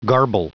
Prononciation du mot garble en anglais (fichier audio)